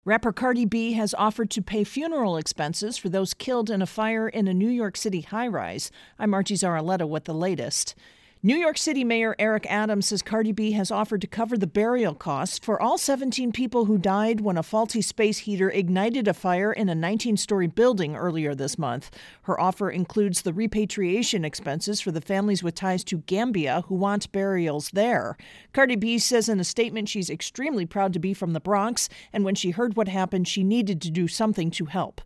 intro & voicer for Apartment Building Fire-Cardi B